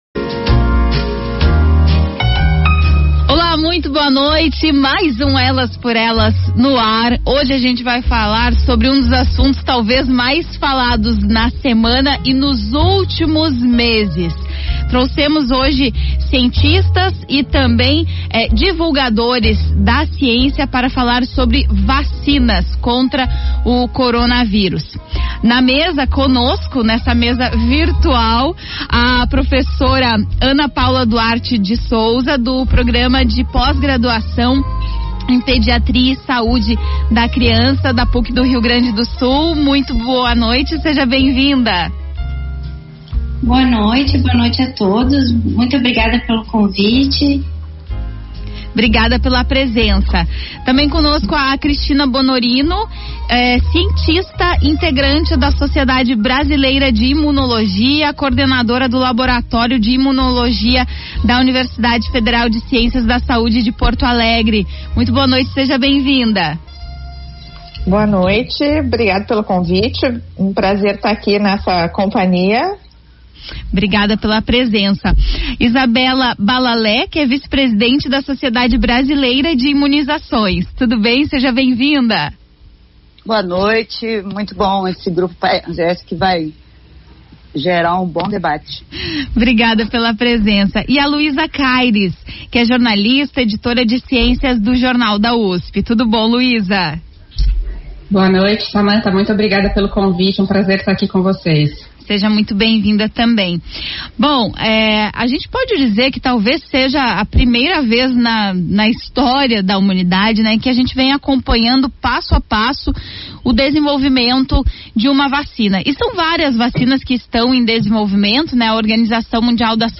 Debate sobre vacinas contra o coronav�rus